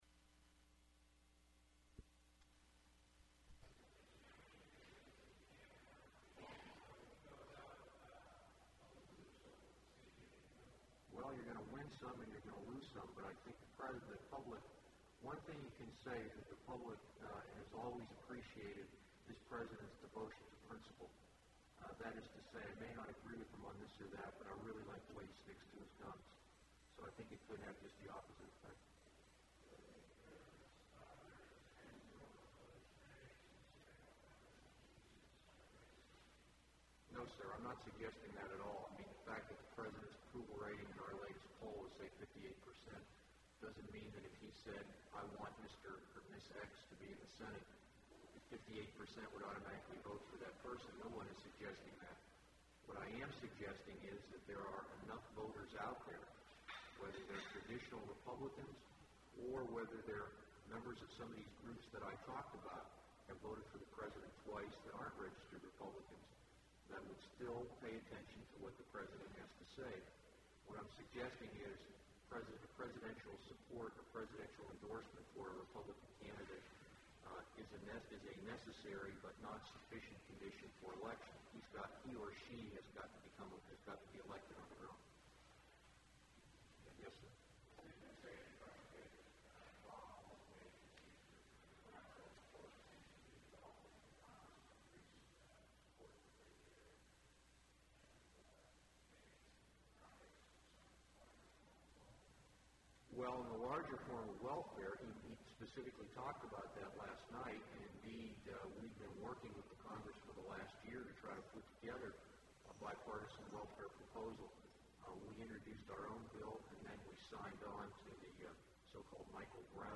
Frank Donatelli’s remarks at end of Briefing for Follow up of State of The Union Briefing